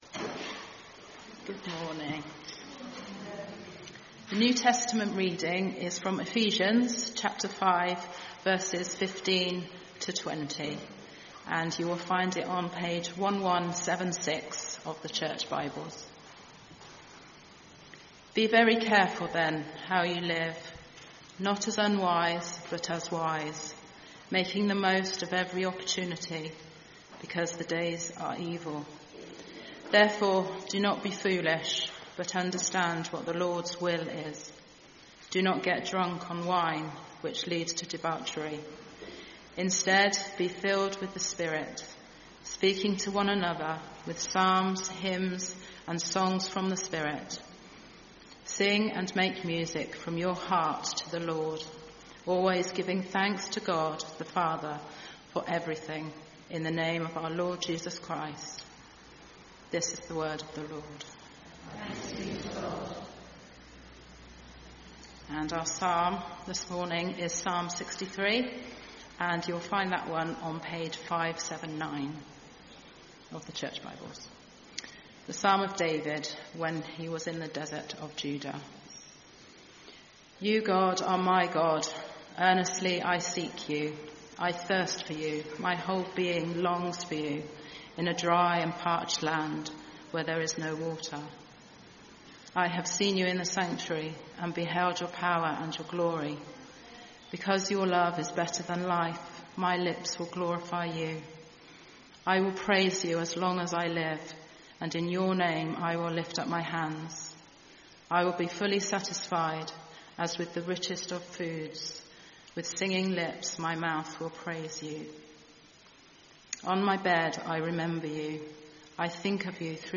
Sunday Sermon 3 August 2025